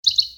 Звуки животных , Пение птиц